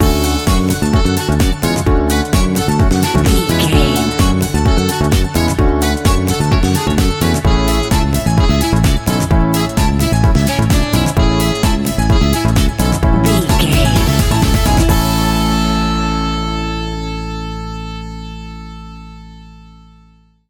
Ionian/Major
groovy
uplifting
bouncy
electric guitar
bass guitar
drums
synthesiser
saxophone
electro
disco